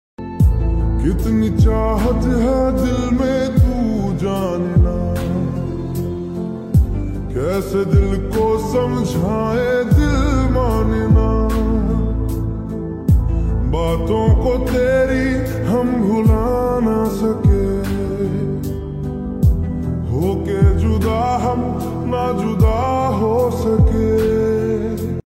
sad ringtone